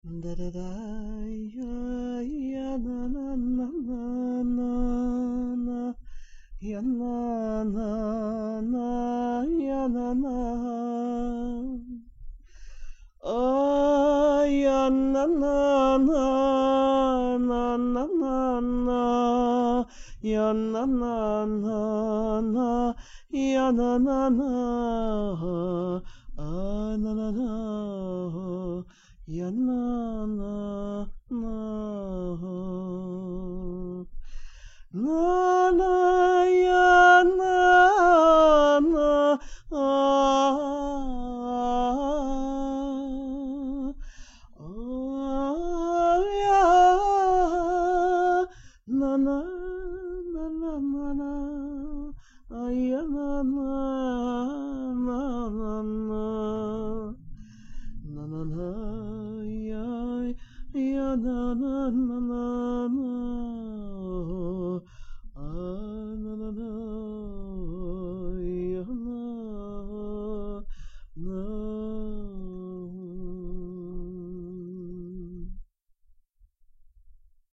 A truly beautiful devekes nigun. This nigun is also used as the setting for the shabbat song Yah Ekhsof.